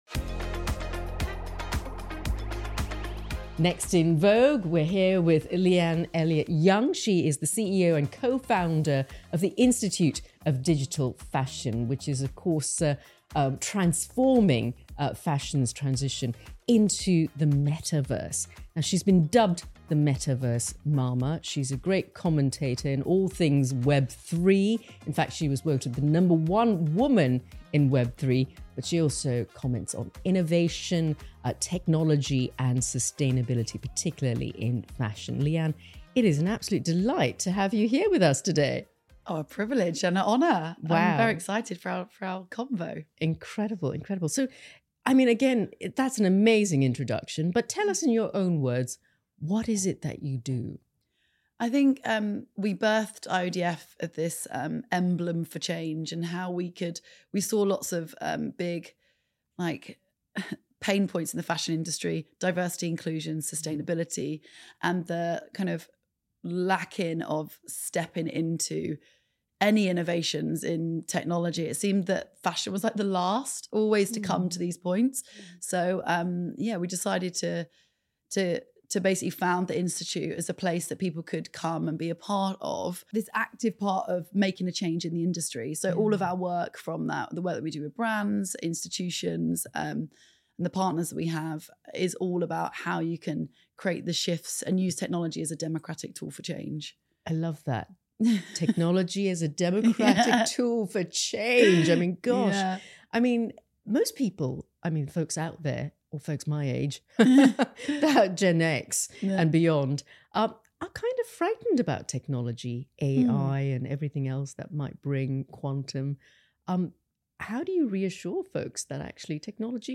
Hosted by the editors of Vogue Singapore, each episode discusses critical topics and issues that women in Singapore face—from the challenges of egg freezing to the balancing act of motherhood and career—of unfiltered and candid conversations with guests that include renowned healers, creatives, entrepreneurs and innovators.